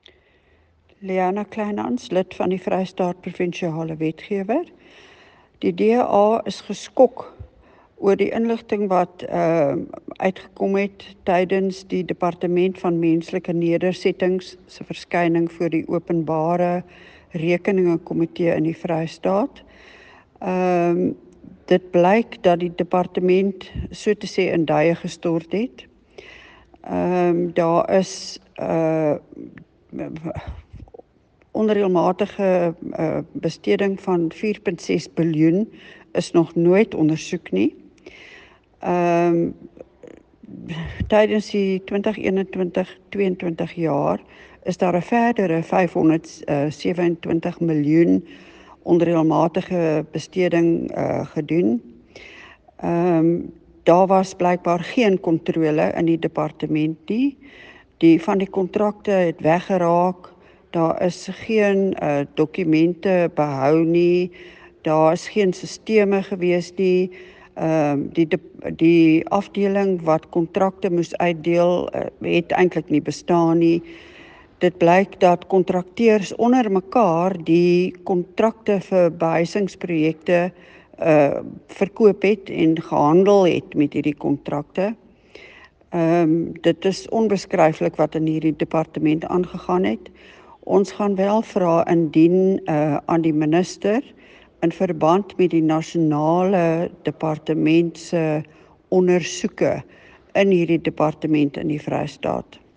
Afrikaans soundbites by Leona Kleynhans MPL